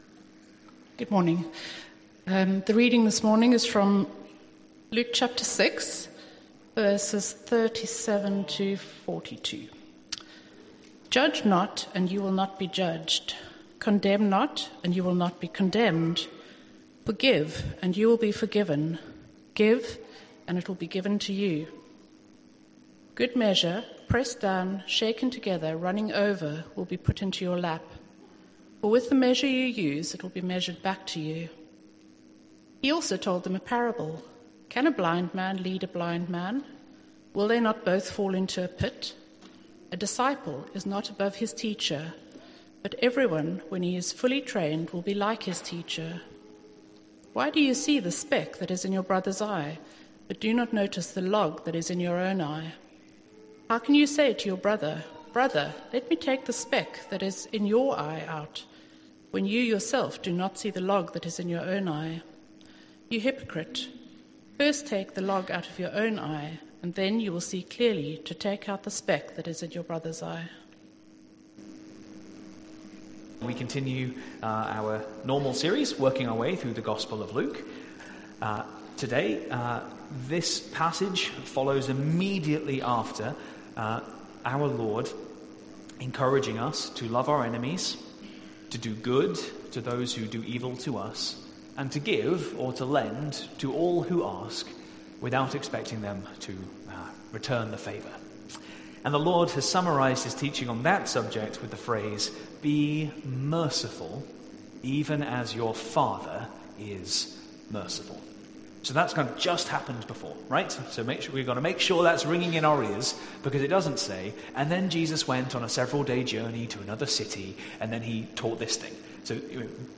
Luke's Gospel Series | #22 - Sermon on the Plain part 3 - Hereford Baptist Church – Spirit, Ministry, Mission